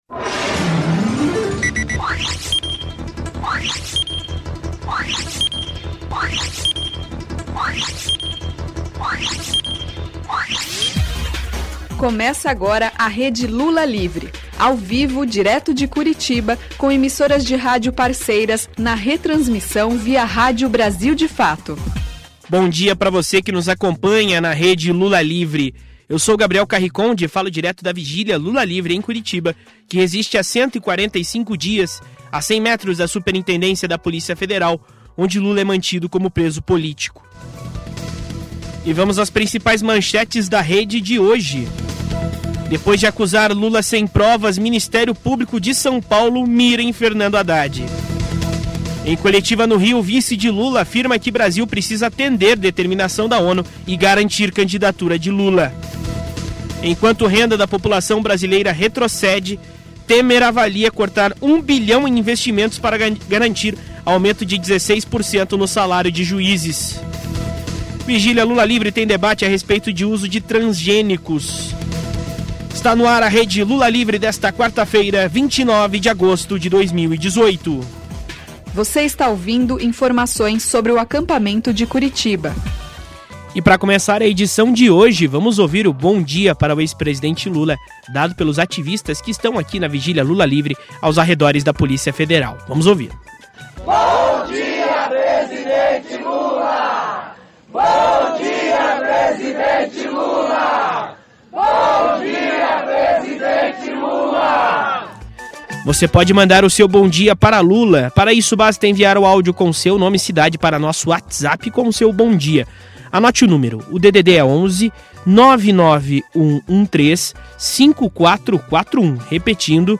Qualquer emissora que desejar pode entrar em rede na transmissão ao vivo, de segunda sexta-feira